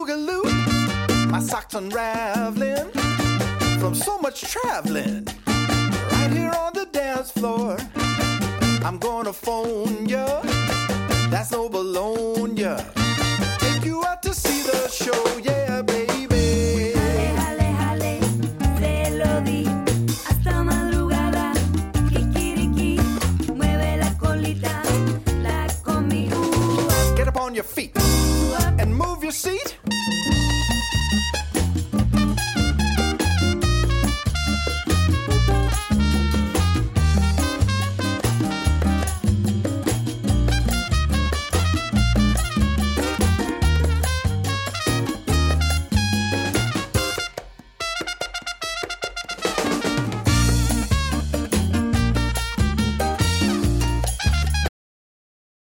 6 piece band
Great for dancing cumbia, meringue, twist.